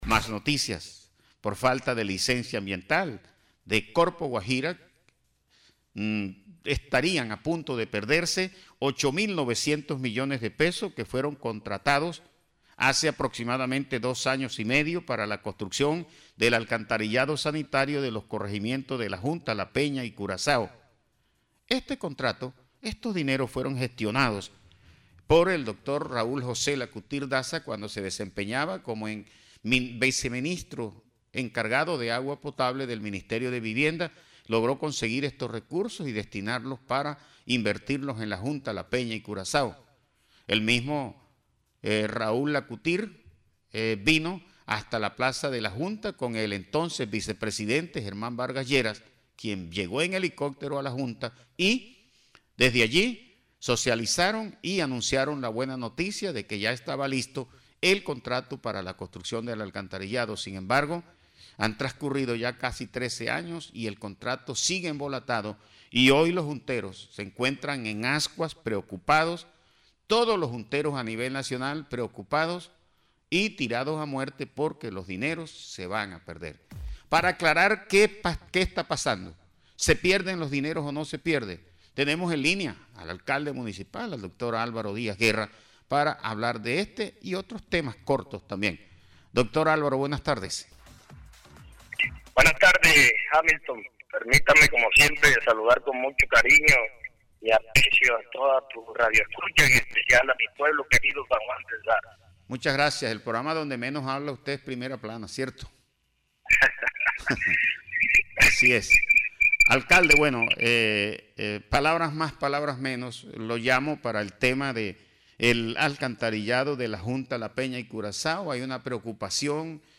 VOZ-ALCALDE-DE-SAN-JUAN-ALVARO-DIAZ-SOBRE-POSIBLE-PERDIDA-DE-RECURSOS-PARA-ALCANTARILLADO-DE-LA-JUNTA.mp3